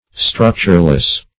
Structureless \Struc"ture*less\, a.